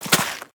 File:Sfx creature snowstalkerbaby walk 02.ogg - Subnautica Wiki
Sfx_creature_snowstalkerbaby_walk_02.ogg